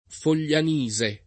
[ fol’l’an &@ e ]